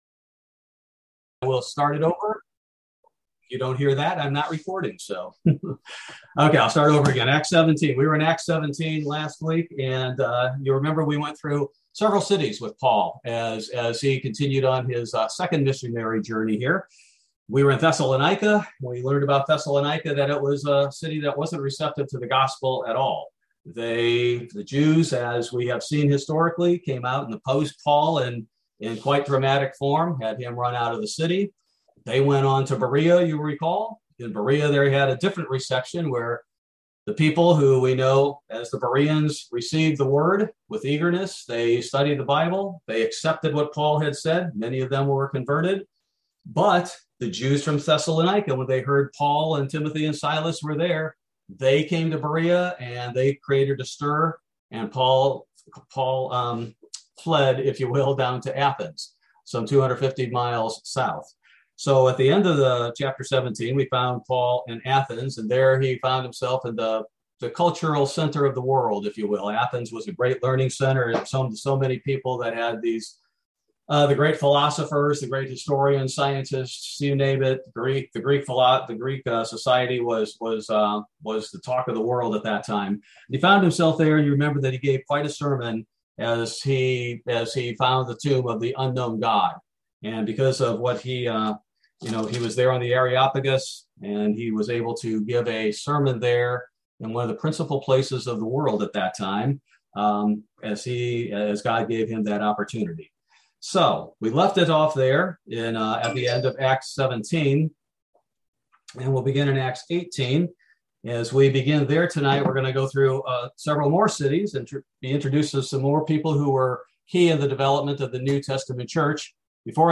Bible Study: November 10, 2021